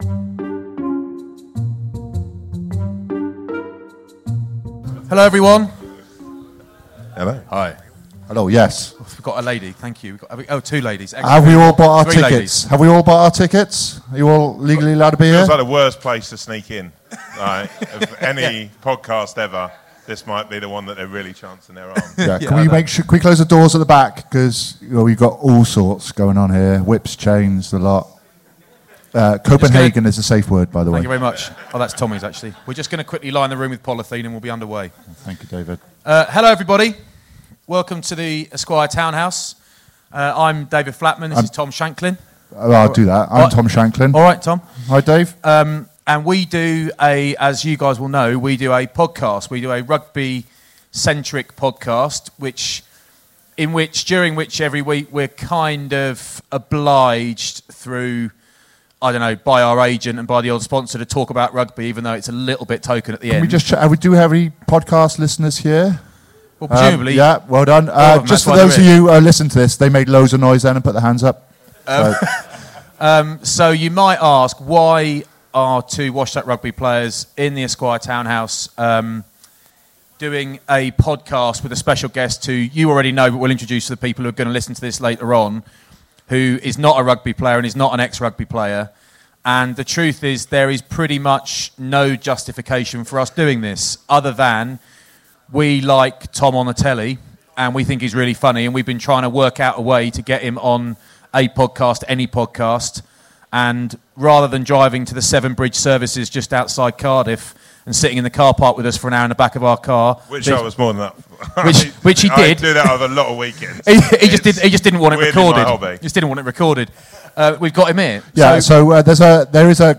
A live podcast in association with Esquire Magazine featuring Bafta Breakthrough winner Tom Davis.